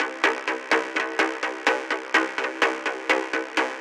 4 синтезатора играют одну и ту же партию - чистые восьмые, выходы идут на шину, на шине есть плагин с задержкой, может это он рисует такую картину, сейчас потестить пока времени нет